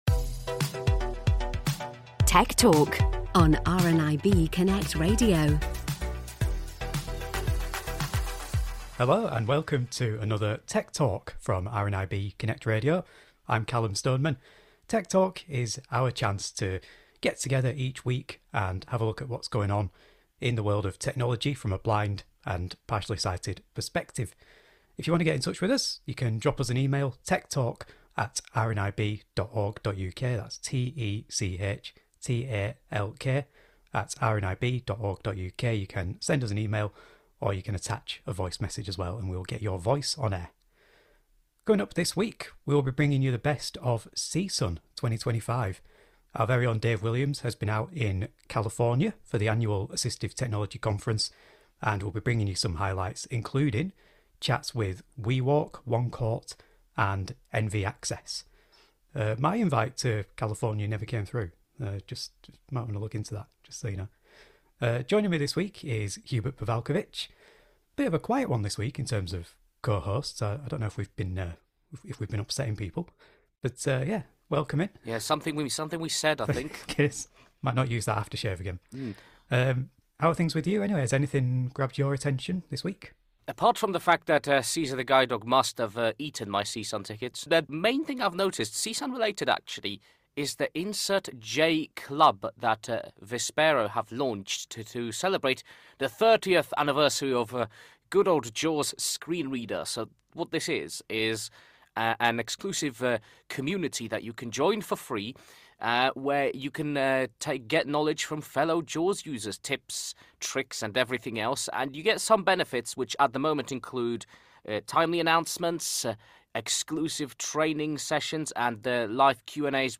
This week, we’ll be bringing you conversations from CSUN 2025.